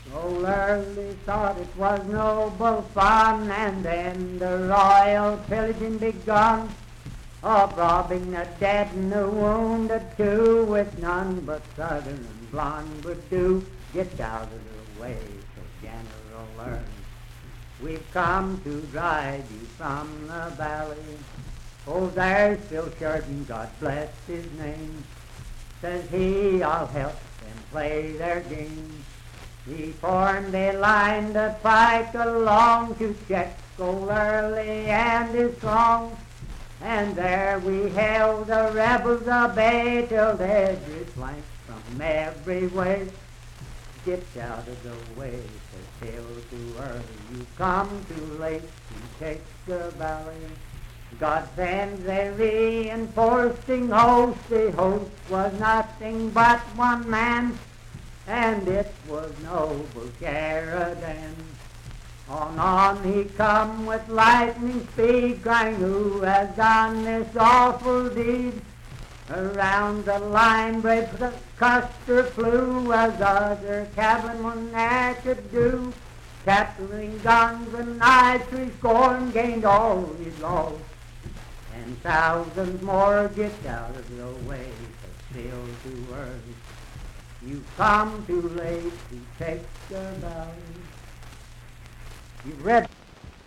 Voice (sung)
Parkersburg (W. Va.), Wood County (W. Va.)